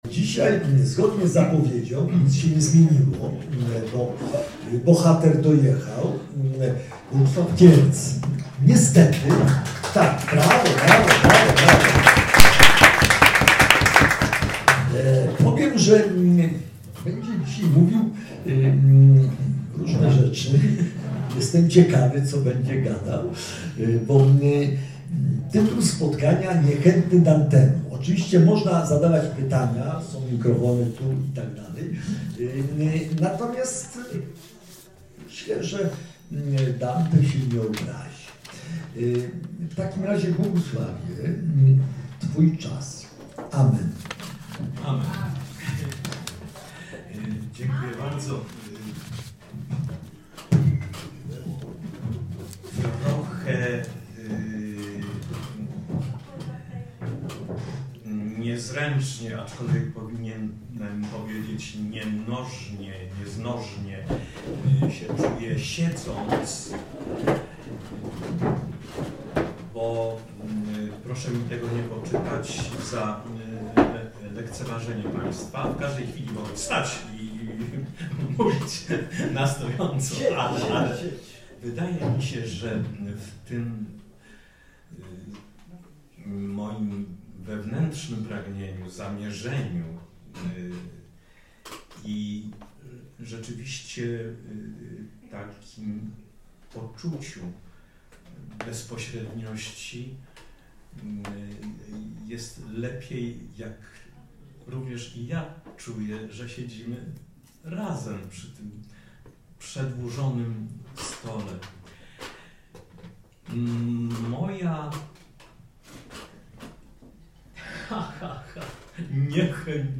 Posłuchaj wykładu Bogusława Kierca Niechętny Dantemu